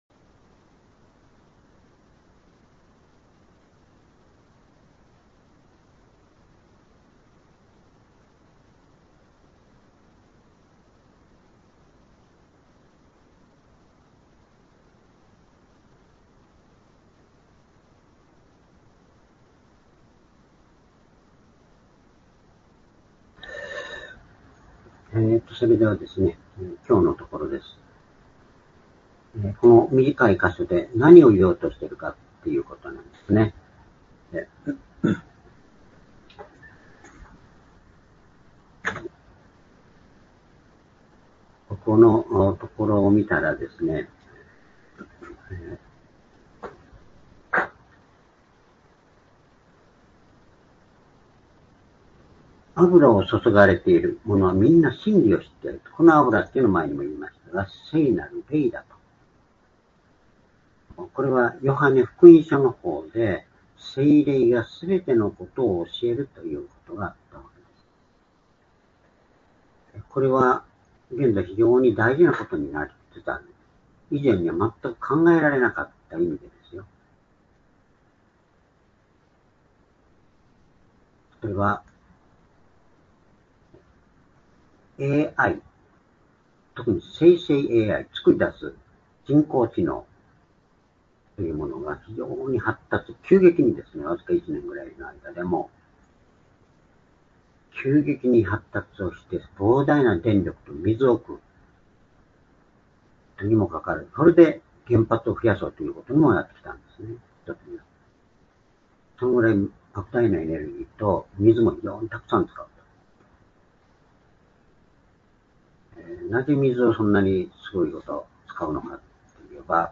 主日礼拝日時 ２０２５年８月３１日（主日礼拝） 聖書講話箇所 「御子キリストの重要性」 Ⅰヨハネ２の２０-２６ （その２） ※視聴できない場合は をクリックしてください。